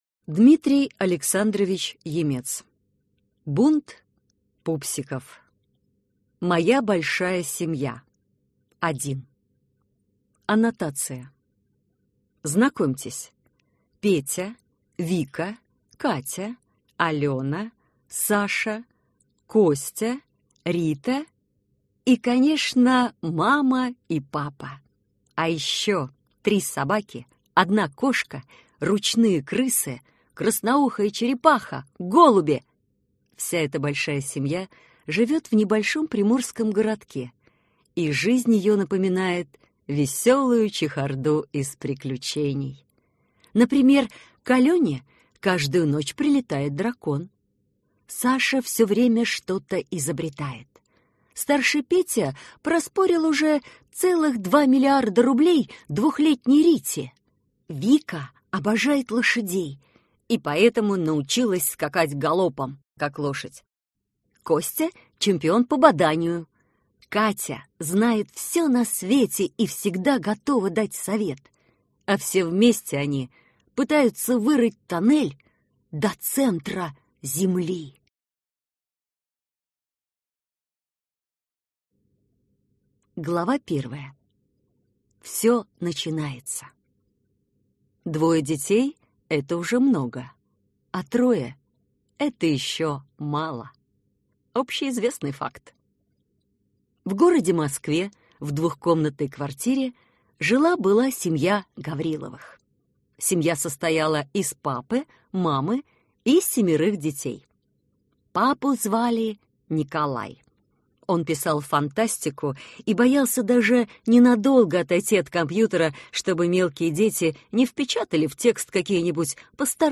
Аудиокнига Бунт пупсиков | Библиотека аудиокниг